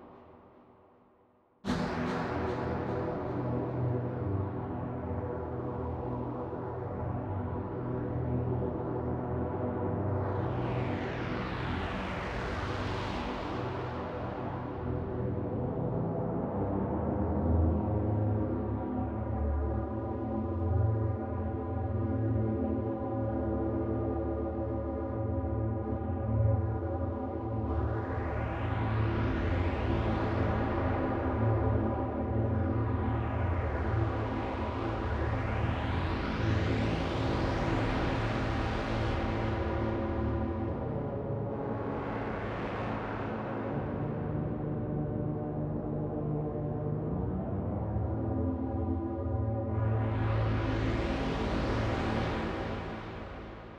Down there in Hades Pad - Audionerdz Academy
Down-there-in-Hades-Pad.wav